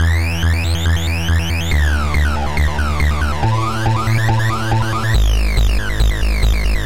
Tag: 14 bpm Dubstep Loops Synth Loops 1.16 MB wav Key : Unknown